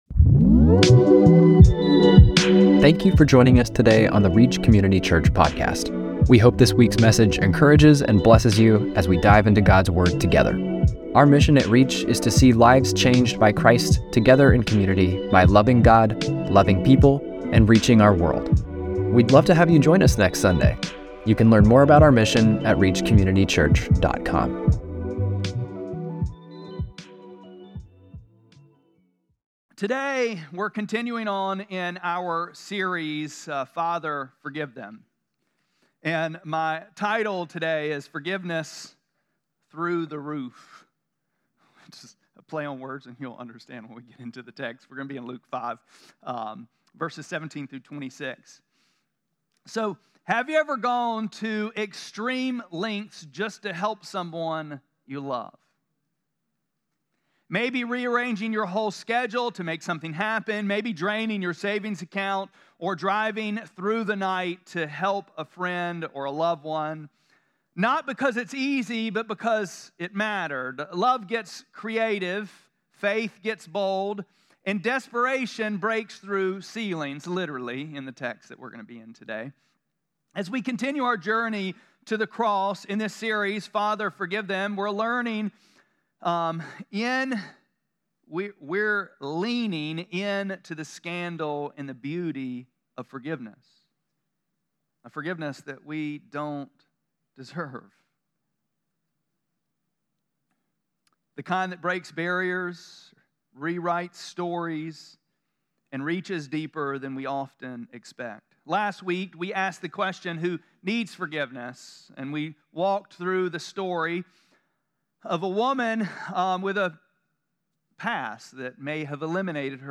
4-13-25-Sermon.mp3